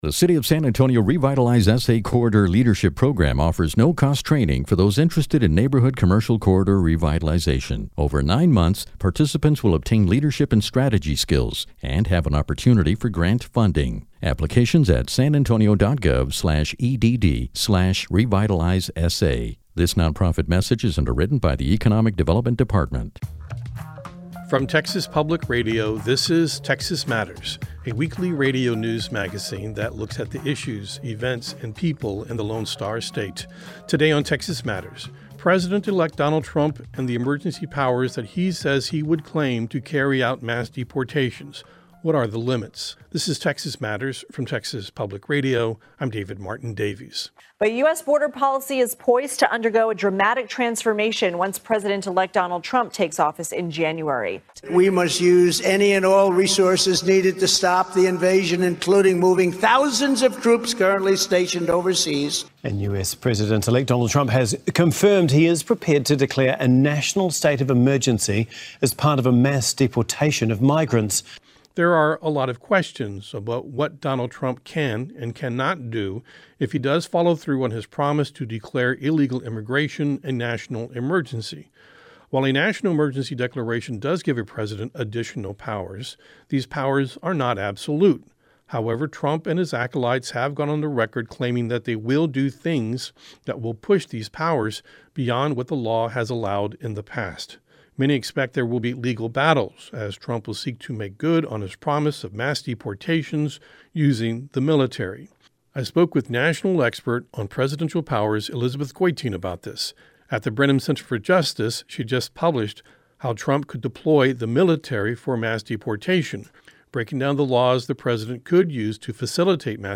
Texas is a big state with a growing, diverse population and as the population grows, the issues and challenges facing its residents multiply. Texas Matters is a statewide news program that spends half an hour each week looking at the issues and culture of Texas.